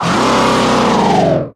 Audio / SE / Cries / GOLISOPOD.ogg